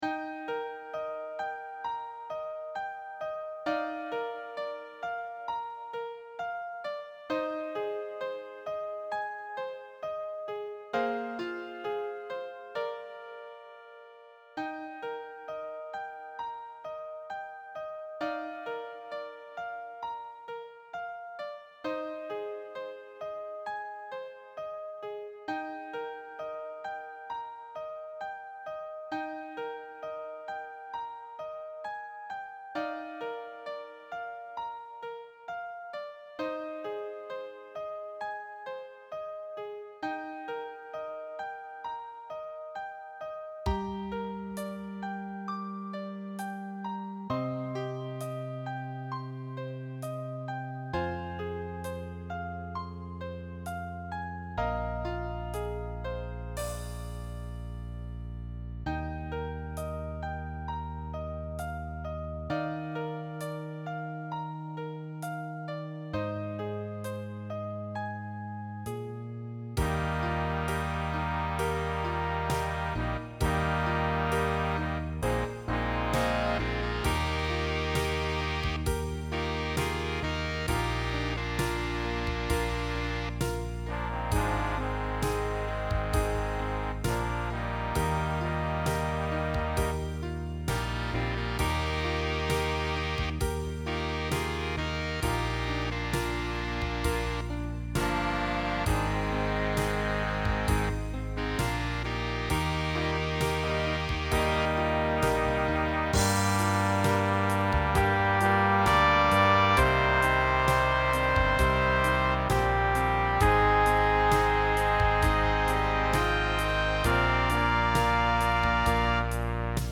Een arrangement voor vocal en big band
Vocal, big band